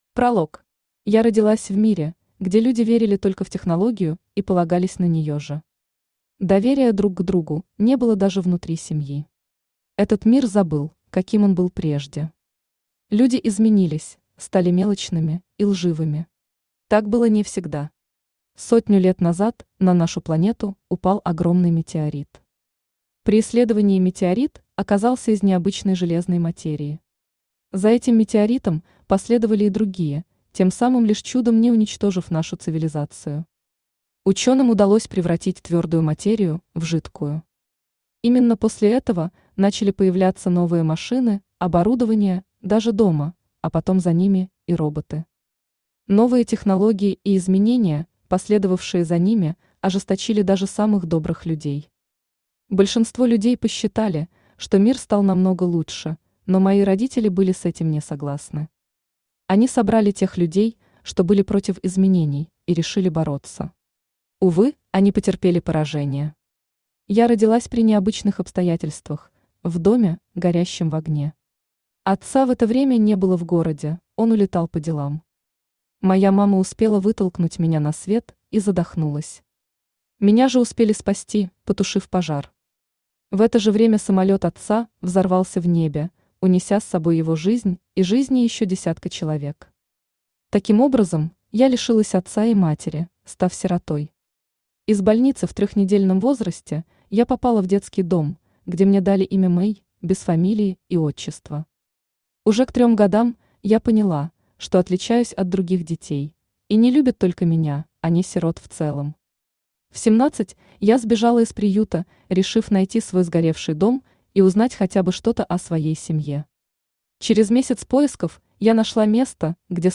Аудиокнига Иная. Погибающий мир | Библиотека аудиокниг